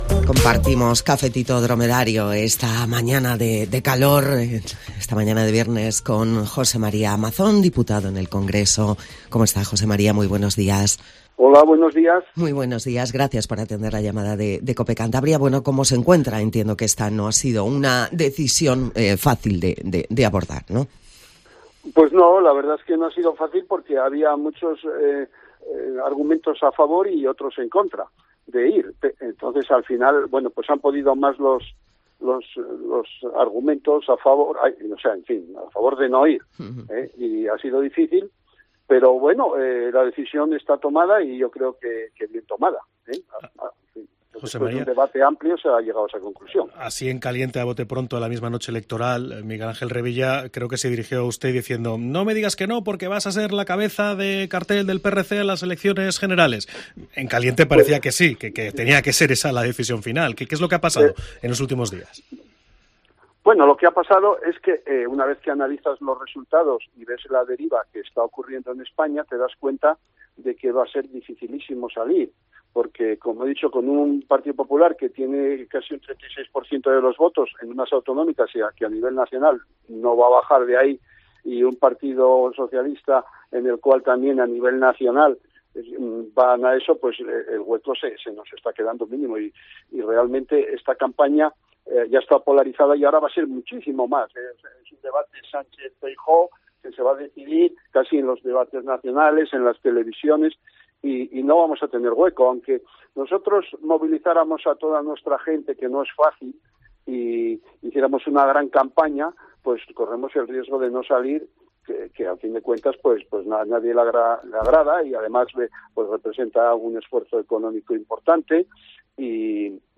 Escucha la entrevista en Cope a José María Mazón (PRC) tras anunciar que no irá a las generales del 23-J